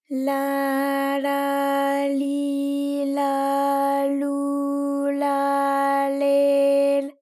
ALYS-DB-001-JPN - First Japanese UTAU vocal library of ALYS.
la_la_li_la_lu_la_le_l.wav